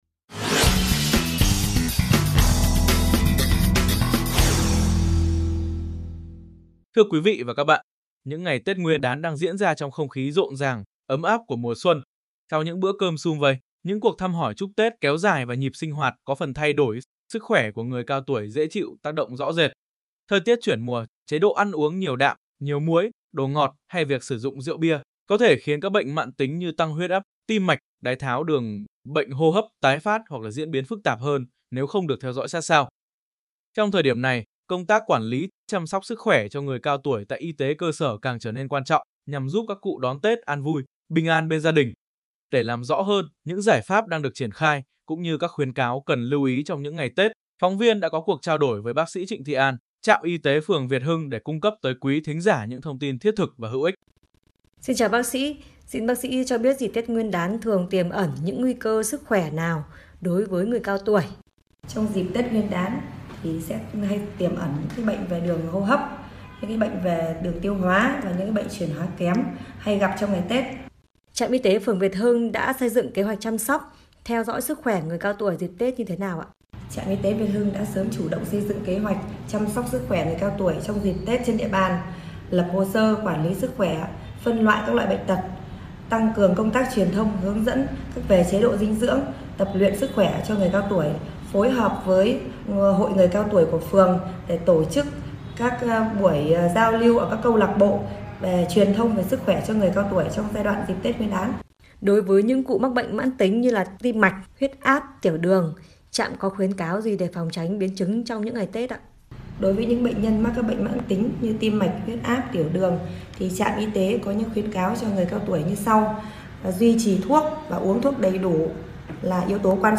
cuộc trao đổi